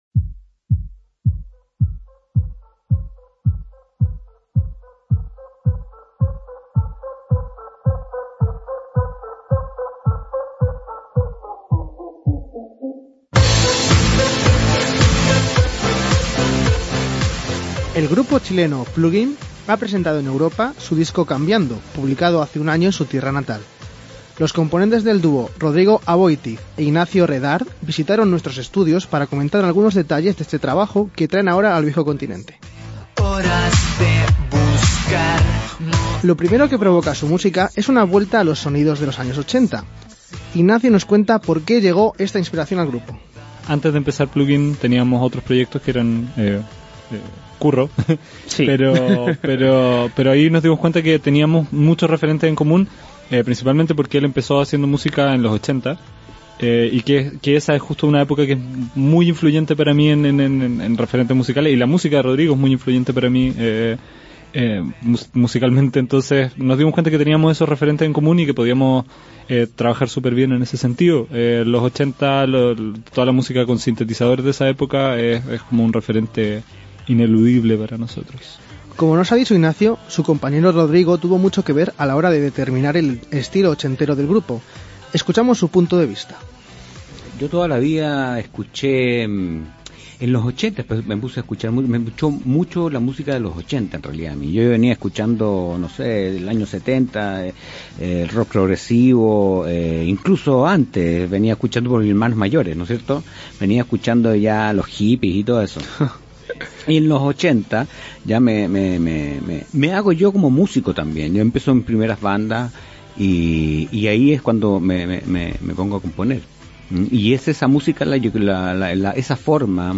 Lo primero que provoca su música es una vuelta a sonidos de los 80.
Al final del mismo se incluye uno de los temas presentes en su nuevo último trabajo. Se trata de ‘Lo mismo’, y el dúo lo interpreta en directo dentro de nuestro estudio.